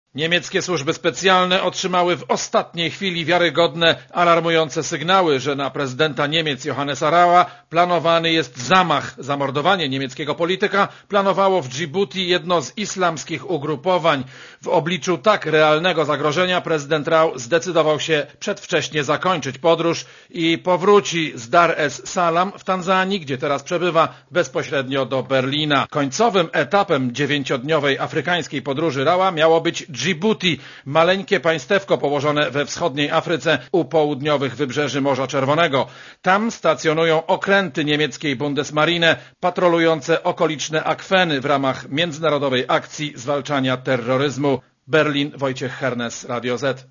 Posłuchaj korespondencji z Berlina